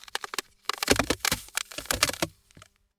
Ice Cracking
A deep, resonant crack spreading across a frozen surface with stress groans and pops
ice-cracking.mp3